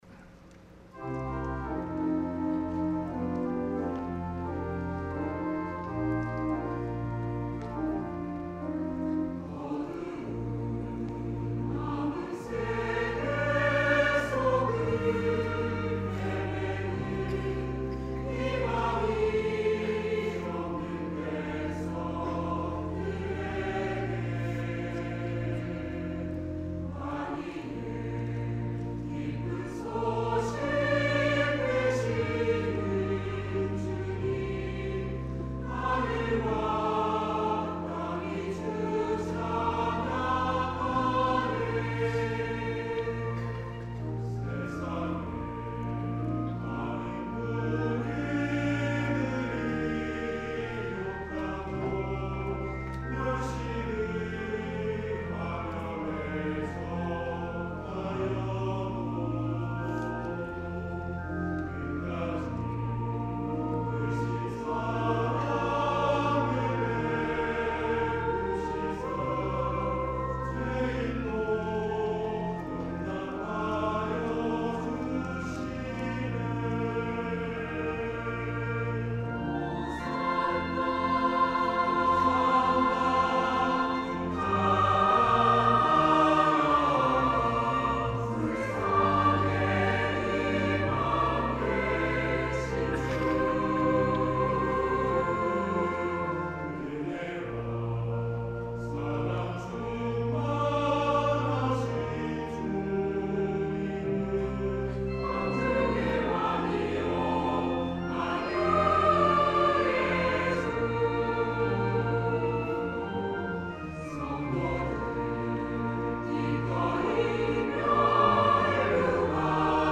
찬양 :: 141207 주는 왕의 왕
" 주는 왕의 왕 "- 시온찬양대